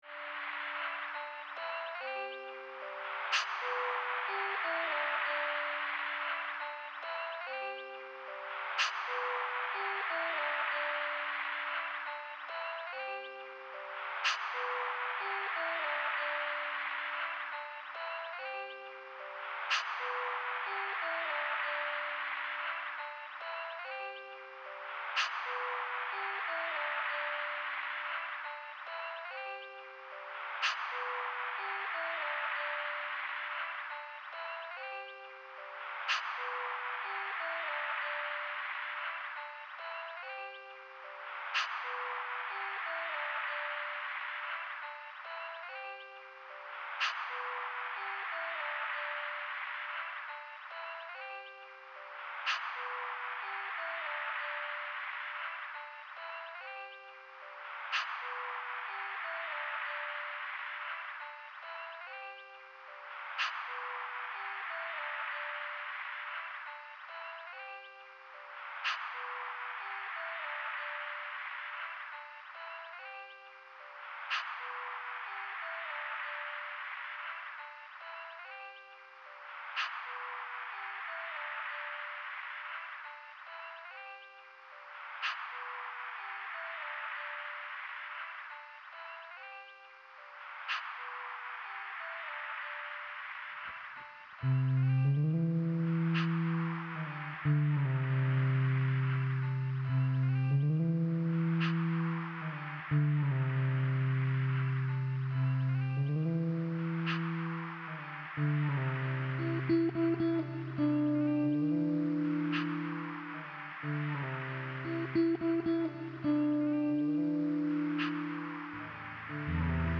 an excerpt of bedtime frippertronics :heart: